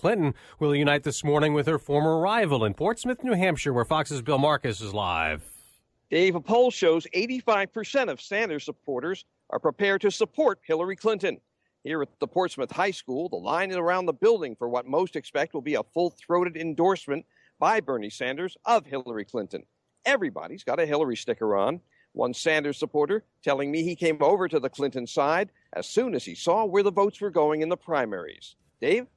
(FOX NEWS RADIO, JULY 12, 9AM, LIVE) –